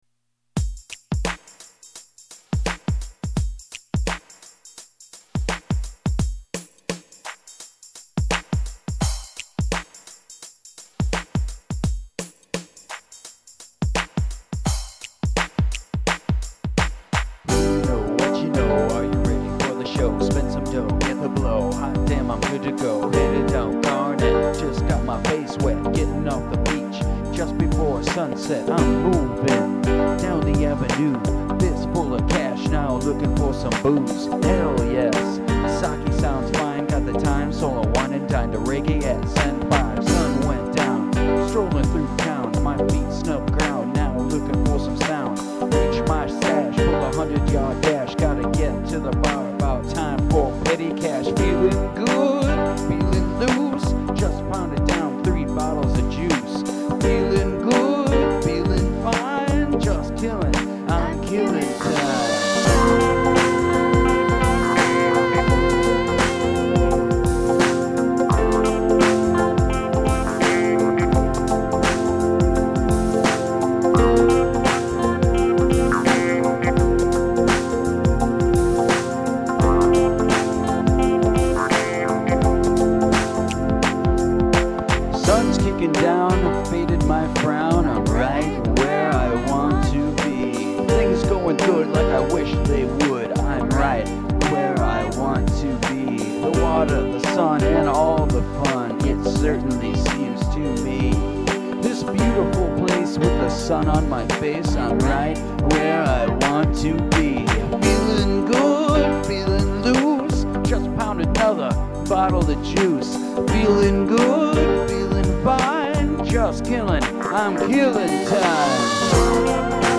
The 2 man rock band.